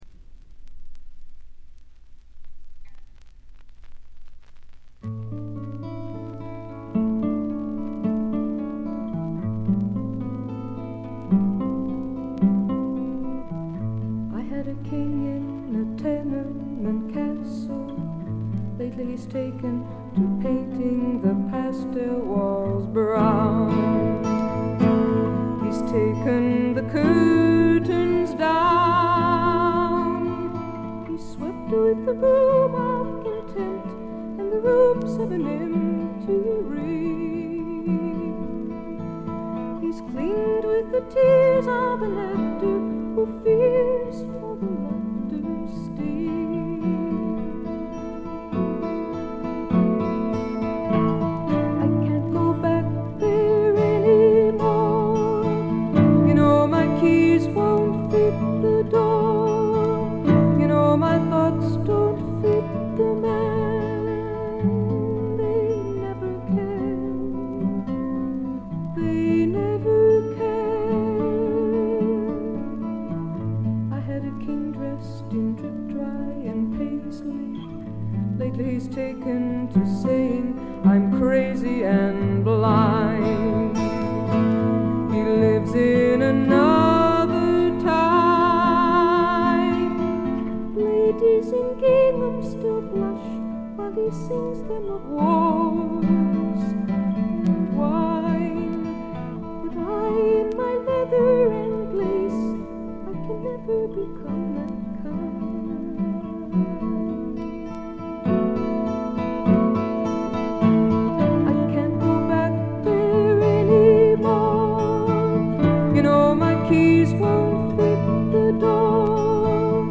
全体にバックグラウンドノイズ。ところどころで軽微なチリプチ（特にA面冒頭）。
至上の美しさをたたえたサイケ・フォーク、アシッド・フォークの超絶名盤という見方もできます。
試聴曲は現品からの取り込み音源です。
guitar, piano, vocals